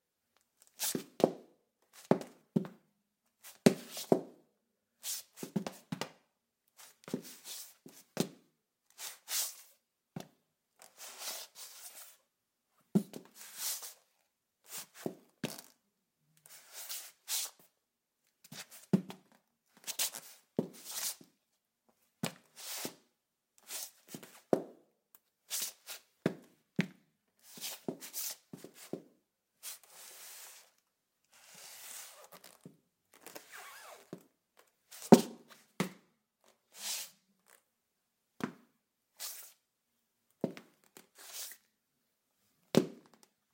随机 " 脚步声 靴子 鞋子 泥土 碎石 走路 短暂 停顿 擦伤
描述：脚步靴靴子泥土砾石步行短暂停止scuff.wav
标签： 脚步 碎石 靴子 停止 污垢 耐磨损
声道立体声